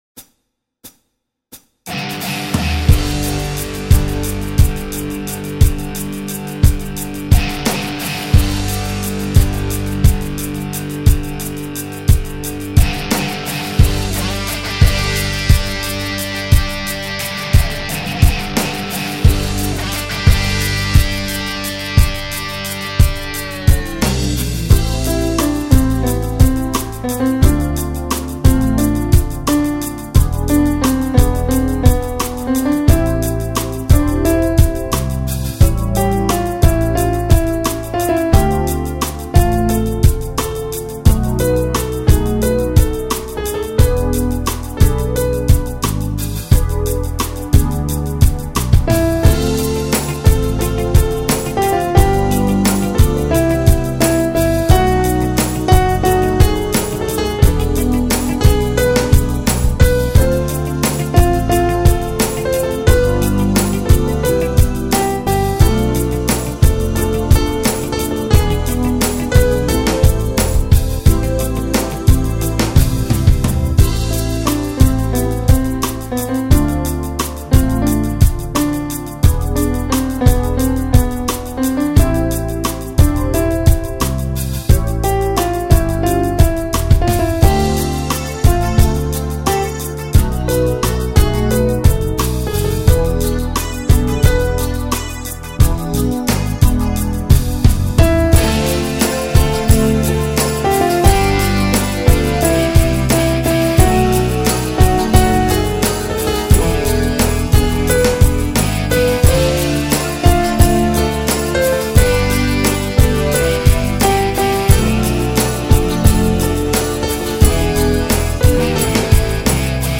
(Для жіночого виконання) )